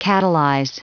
Prononciation du mot catalyze en anglais (fichier audio)
Prononciation du mot : catalyze